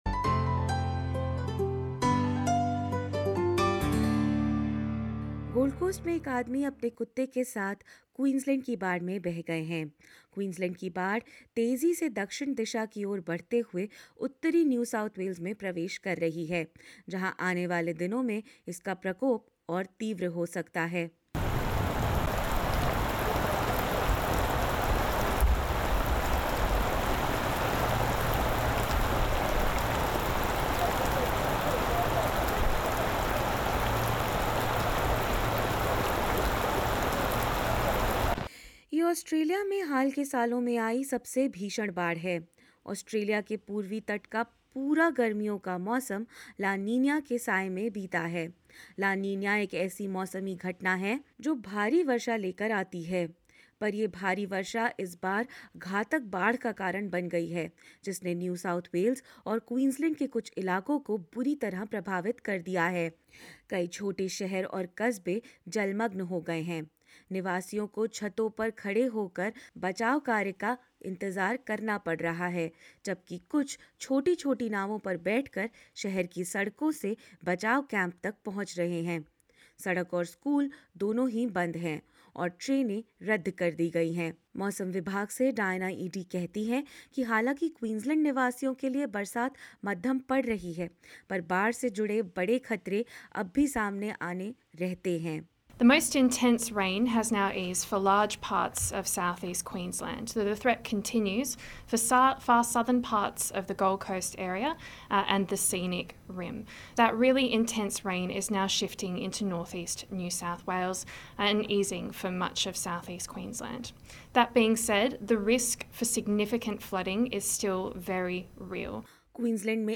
राज्य और फ़ेडरल आपातकालीन सेवाओं के साथ-साथ ऑस्ट्रेलियाई सैन्य बल भी राहत कार्य में जुटे हैं। कैसी है इन जगहों की परिस्थिति, किस तरह हो रहा है बचाव कार्य, और क्या कहना है स्थानीय निवासियों का, जानेंगे इस विस्तृत रिपोर्ट में।